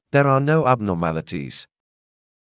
ｾﾞｱ ﾗｰ ﾉｰ ｱﾌﾞﾉｰﾏﾘﾃｨｽﾞ